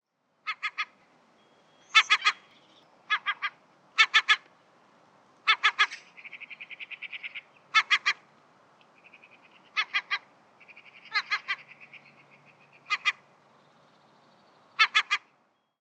Vocalizations: What Do Baby Seagulls Sound Like?
Gull chicks emit a high-pitched “peep” or squeaky “eeep-eeep” to communicate hunger or alert distress.